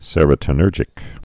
(sĕrə-tn-ûrjĭk) also se·ro·to·ni·ner·gic (-tōnə-nûrjĭk)